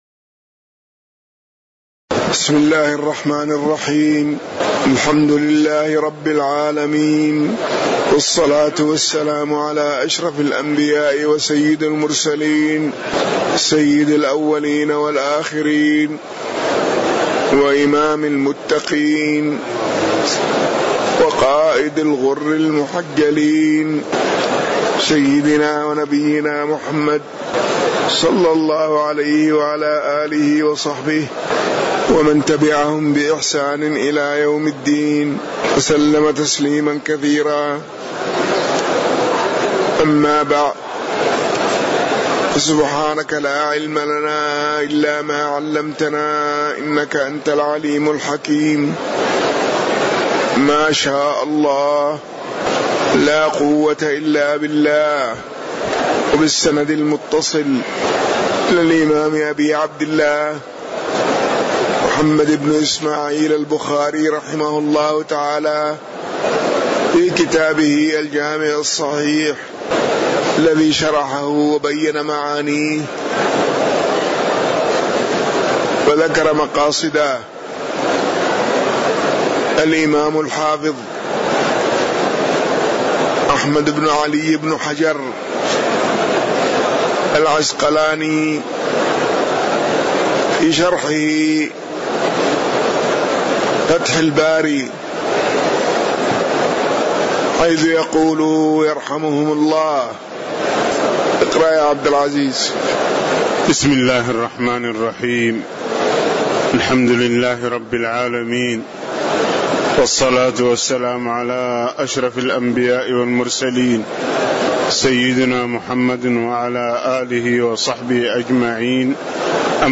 تاريخ النشر ١٠ جمادى الآخرة ١٤٣٩ هـ المكان: المسجد النبوي الشيخ